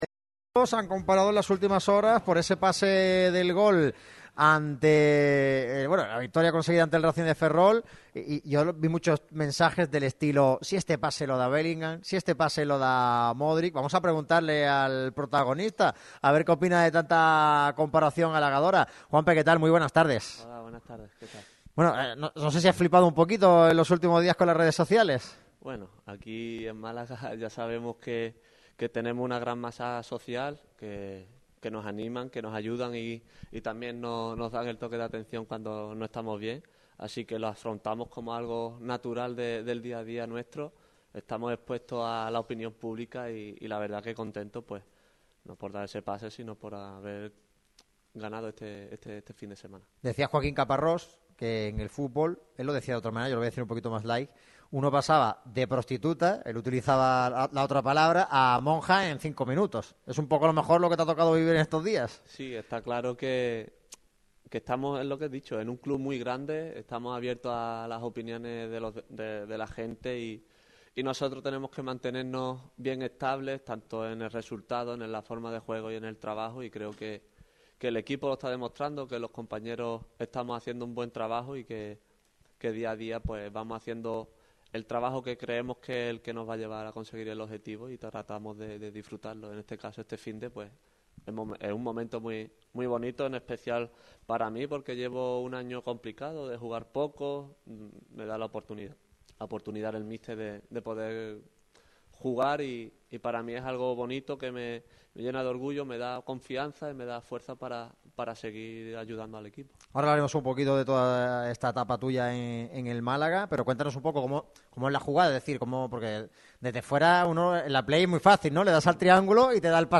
El centrocampista del Málaga CF, Juanpe, ha pasado por el micrófono de Radio MARCA Málaga en una entrevista exclusiva con motivo de la previa del próximo choque de los blanquiazules ante el Real Oviedo. El jerezano ha admitido que su etapa en Martiricos no está siendo fácil y que trabaja diariamente para convencer al míster y revertir la situación.